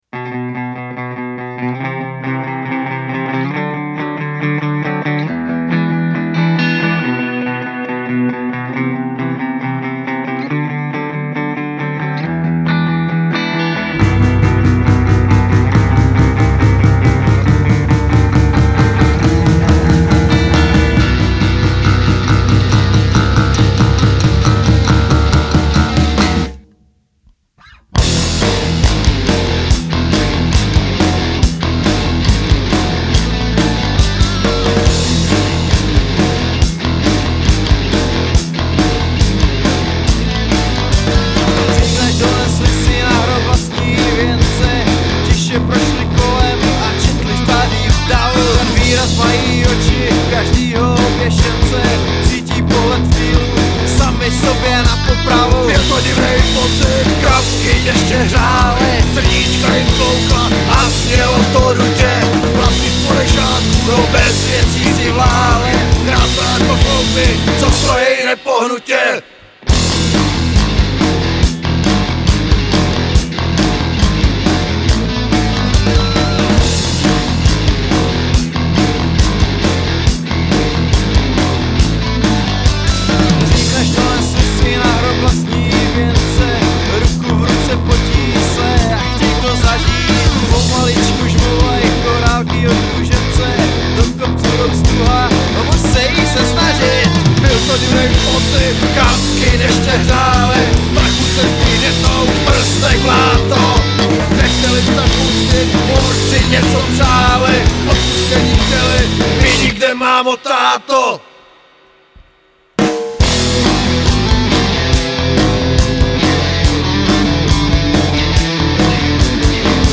Basa = Schecter + GK (linka + mikrofon)